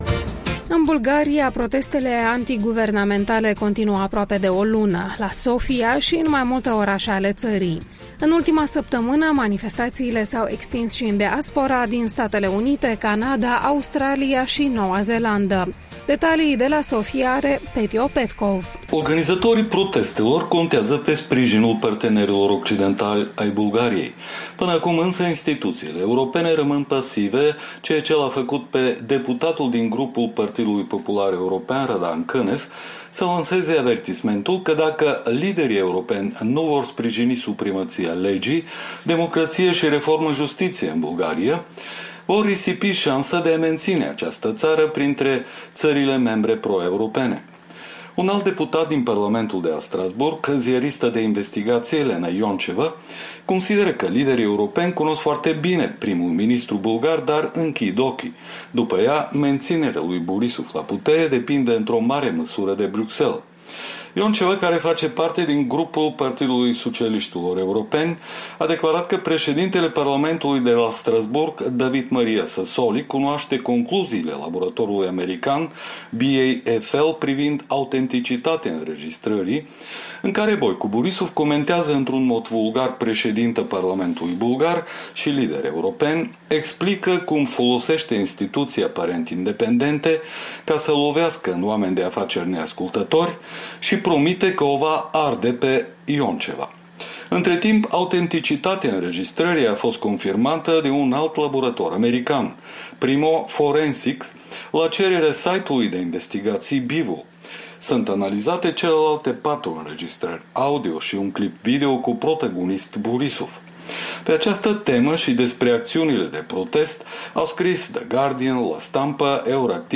Jurnal de corespondent de la Sofia: protestele se extind în diaspora